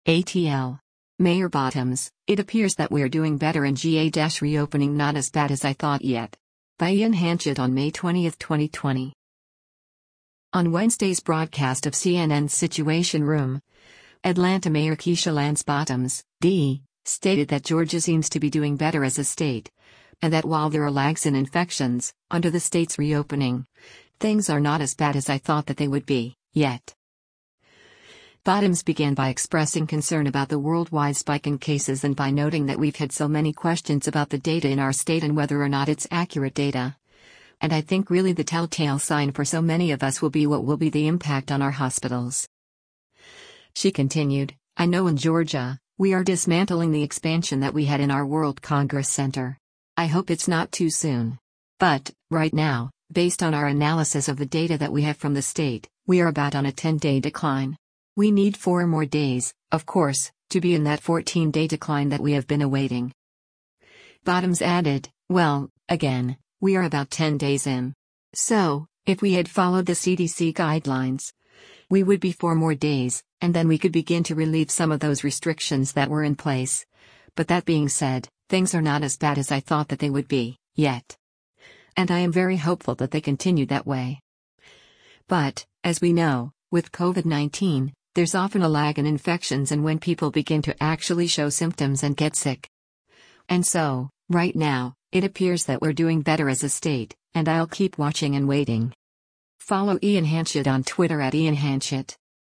On Wednesday’s broadcast of CNN’s “Situation Room,” Atlanta Mayor Keisha Lance Bottoms (D) stated that Georgia seems to be “doing better as a state,” and that while there are lags in infections, under the state’s reopening, “things are not as bad as I thought that they would be, yet.”